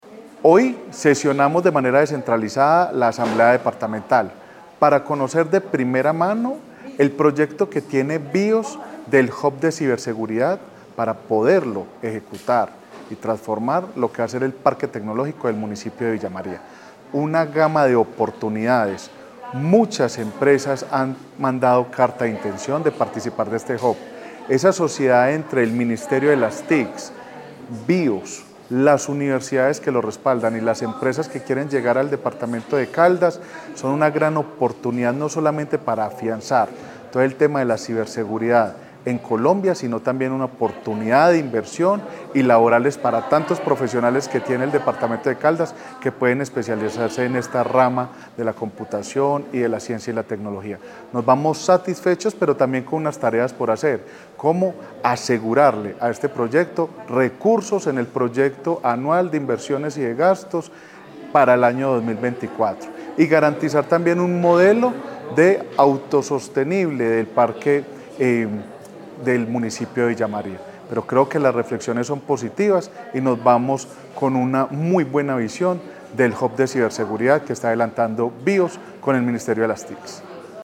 Audio Andrés Chaparro, Presidente de la Asamblea de Caldas